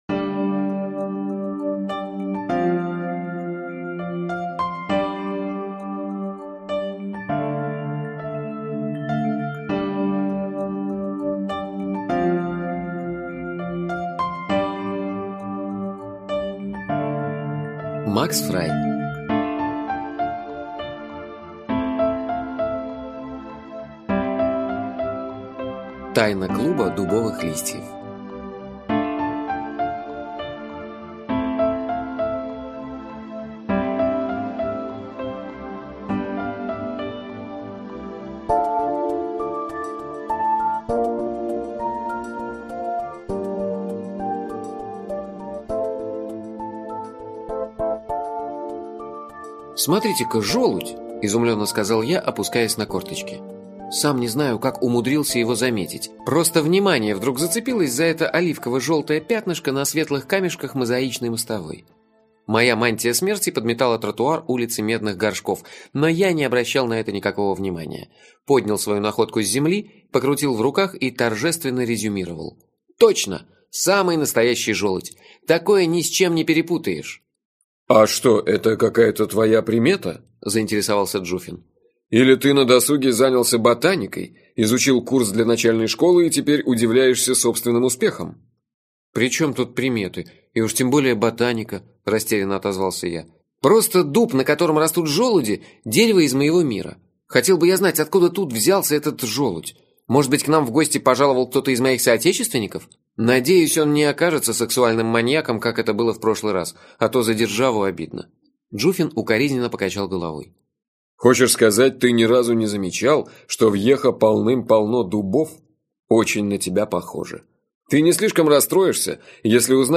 Аудиокнига Болтливый мертвец (сборник) - купить, скачать и слушать онлайн | КнигоПоиск